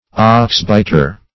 oxbiter - definition of oxbiter - synonyms, pronunciation, spelling from Free Dictionary Search Result for " oxbiter" : The Collaborative International Dictionary of English v.0.48: Oxbiter \Ox"bit`er\, n. (Zool.) The cow blackbird.